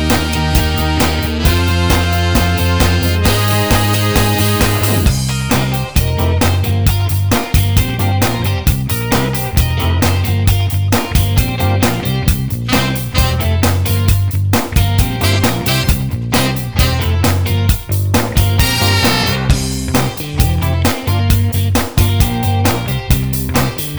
no Backing Vocals Duets 4:16 Buy £1.50